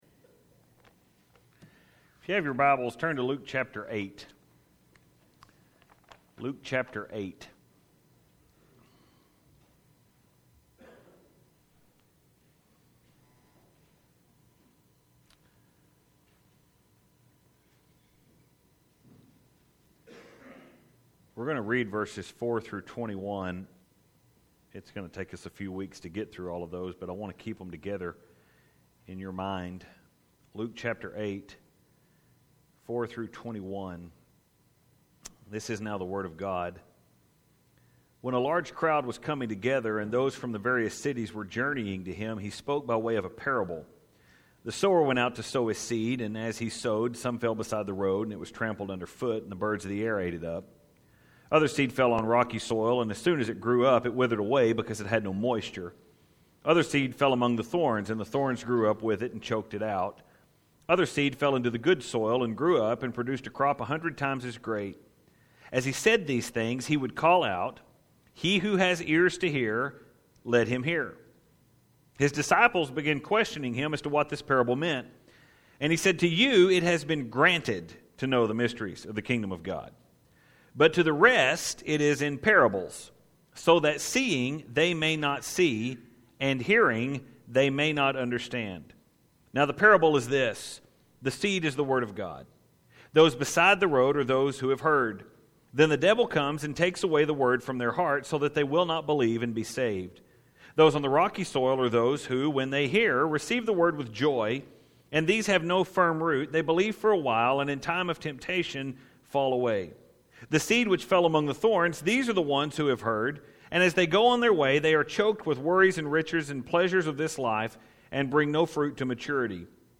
It is a sermon on the importance of listening, And more specifically of listening to what God has to say.